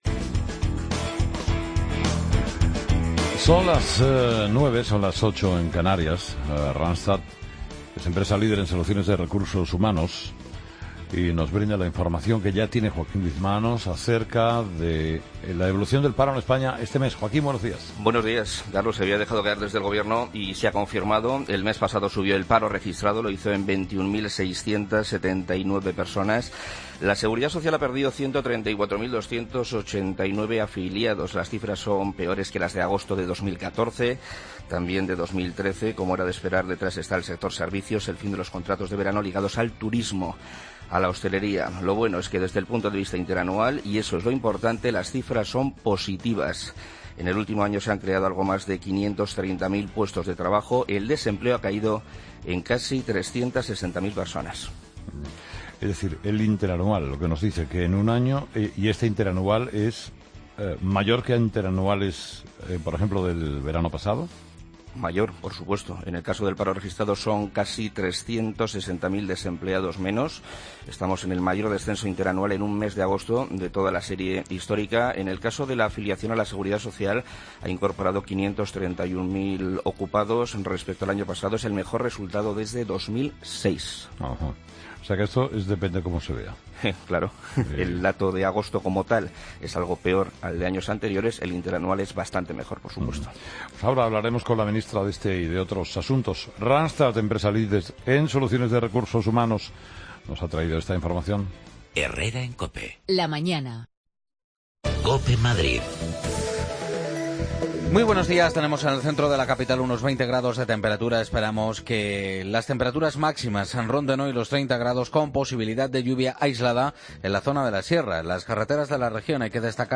Redacción digital Madrid - Publicado el 02 sep 2015, 12:49 - Actualizado 13 mar 2023, 17:25 1 min lectura Descargar Facebook Twitter Whatsapp Telegram Enviar por email Copiar enlace La entrevista del día: Fátima Báñez, Ministra de Empleo.